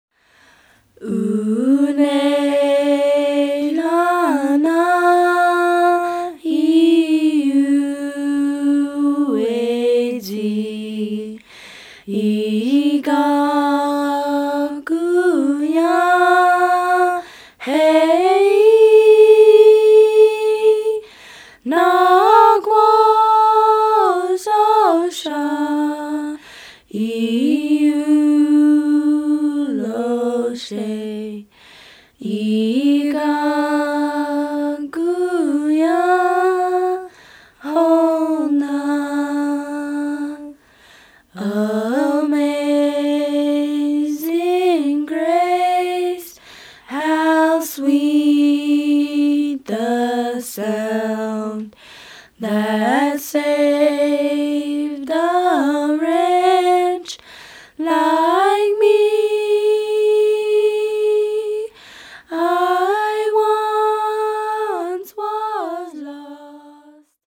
“Amazing Grace” by Cherokee Girls